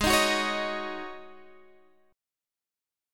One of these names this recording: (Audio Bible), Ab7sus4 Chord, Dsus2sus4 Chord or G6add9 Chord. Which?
Ab7sus4 Chord